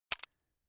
pistol_empty.wav